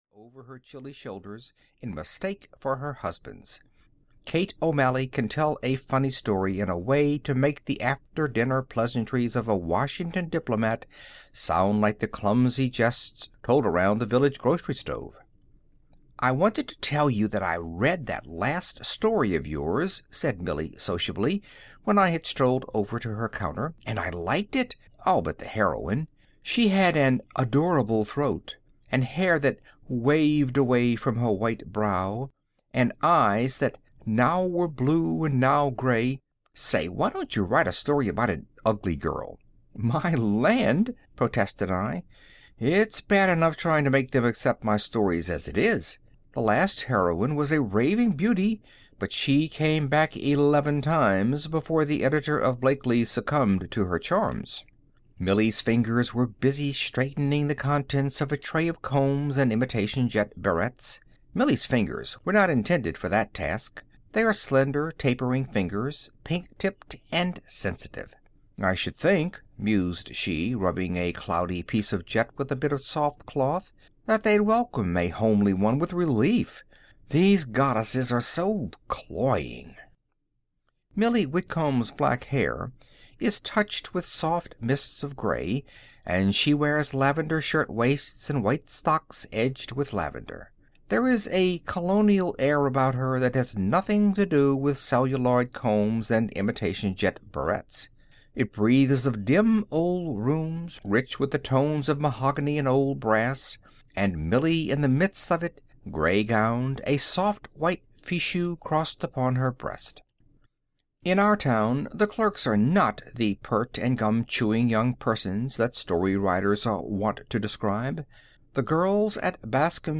The Homely Heroine (EN) audiokniha
Ukázka z knihy